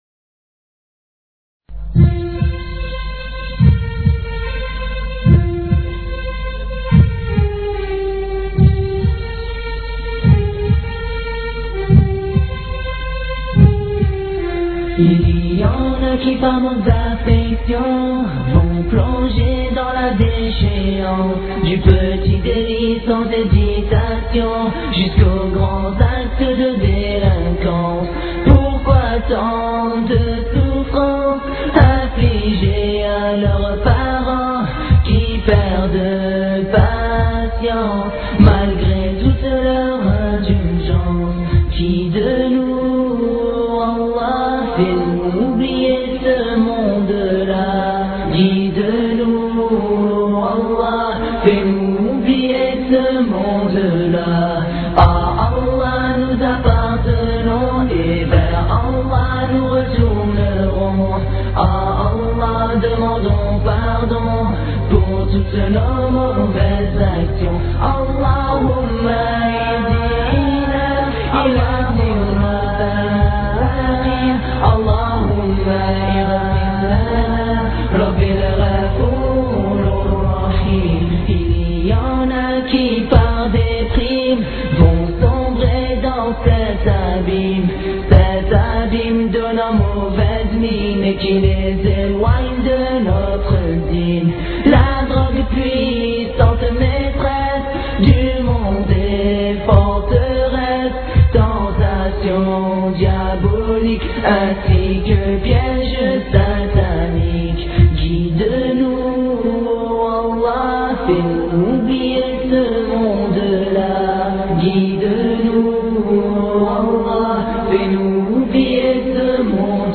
Repentir orateur: quelques jeunes période de temps: 00:00:00